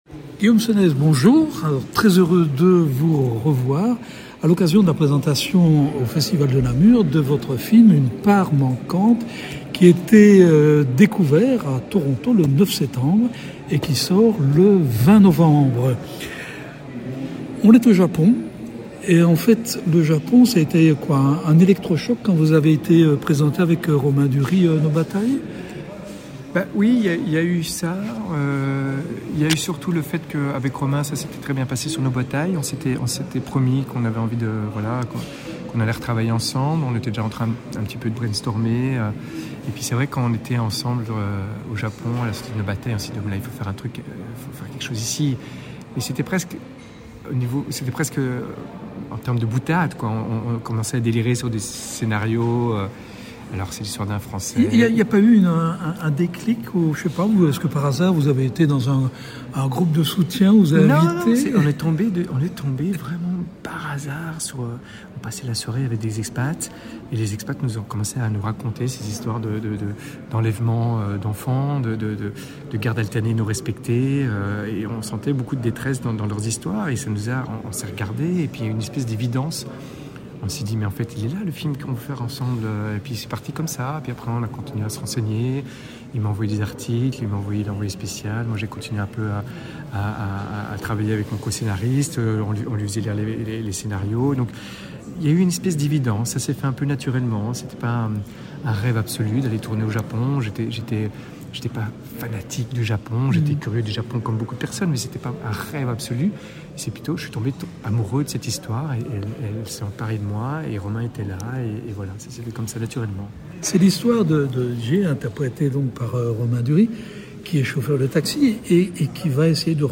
Pour sa troisième mise en scène, le réalisateur belge poursuit son analyse du sacerdoce parental. Un combat universel qu’il a exporté cette fois au pays du soleil levant dans « UNE PART MANQUANTE ». Rencontre.